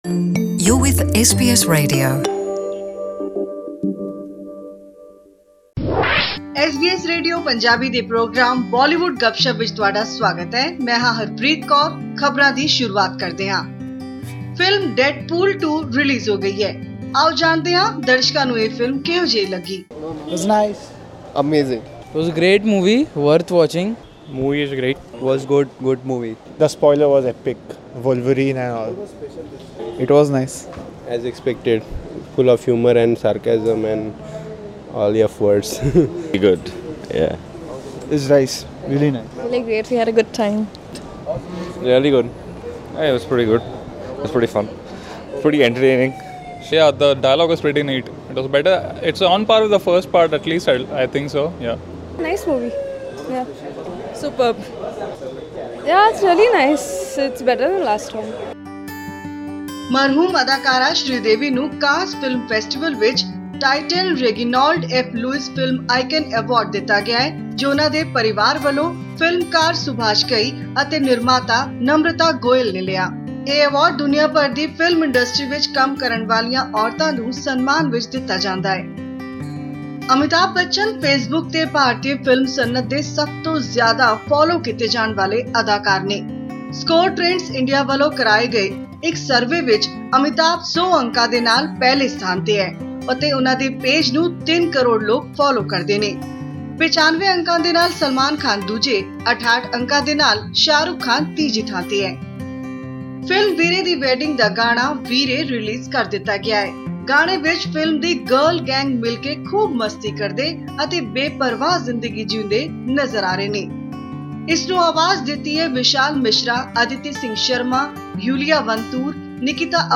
Hear our weekly Bollywood report